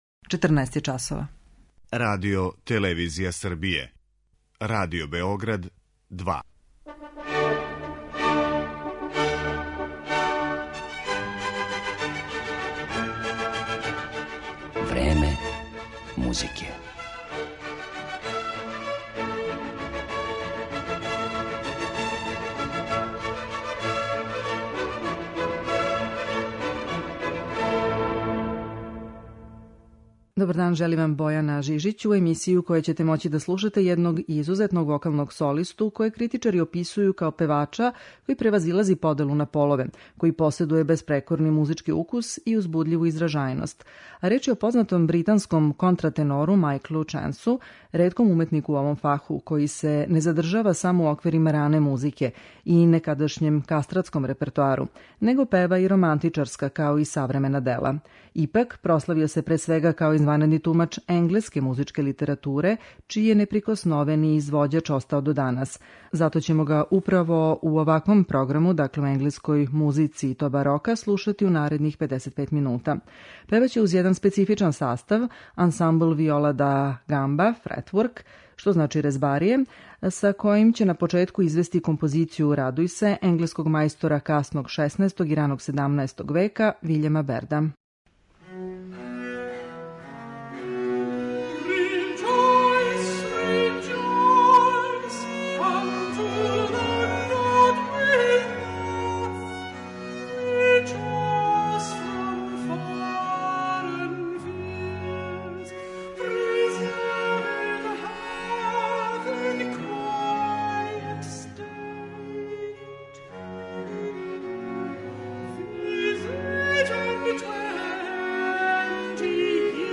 контратенору
у старој енгелској музици.
консортом виола